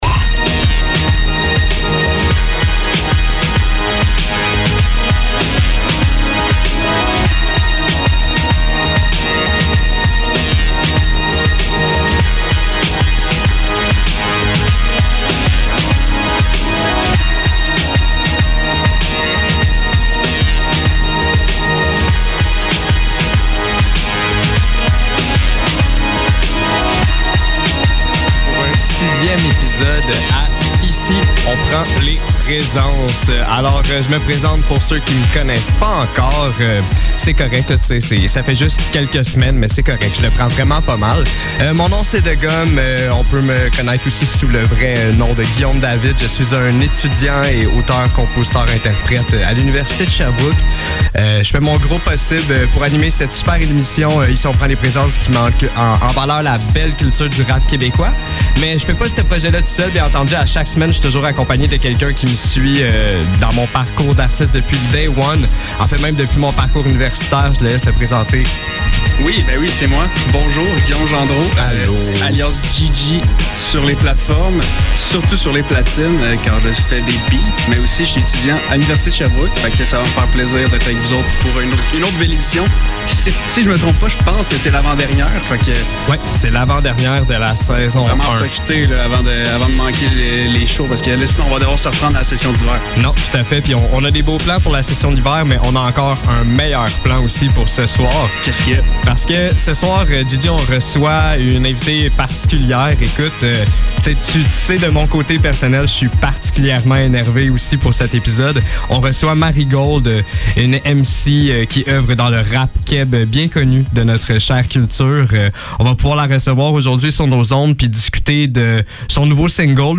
Ici, on prend les présences - Entrevue